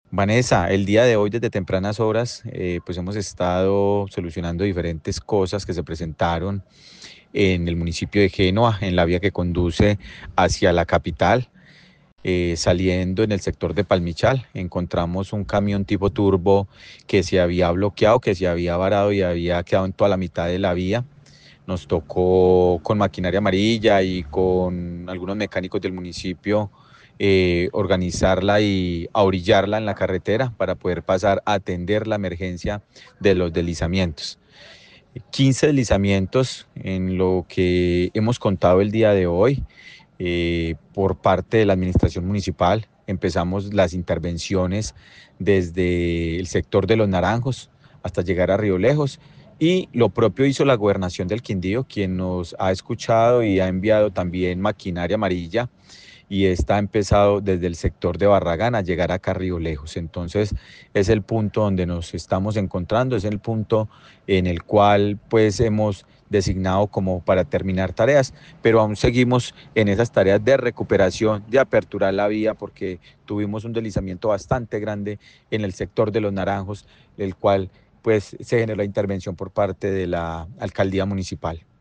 Alcalde de Génova, Diego Fernando Sicua